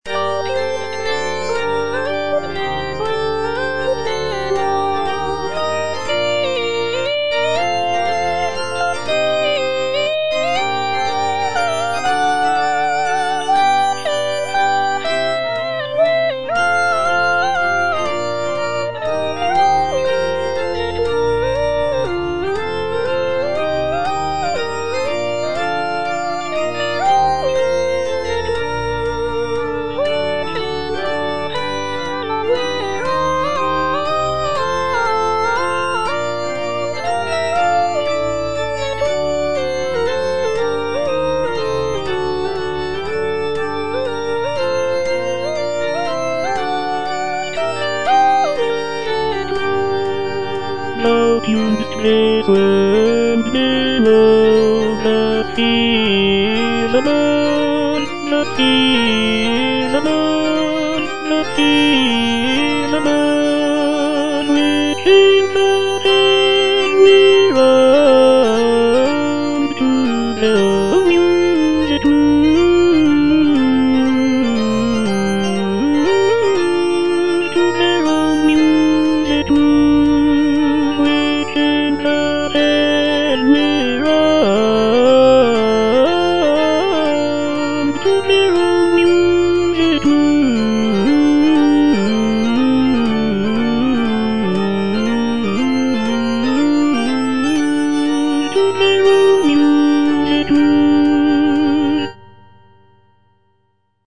H. PURCELL - ODE FOR ST. CECILIA’S DAY, 1692 Thou tuned’st this world - Tenor (Voice with metronome) Ads stop: auto-stop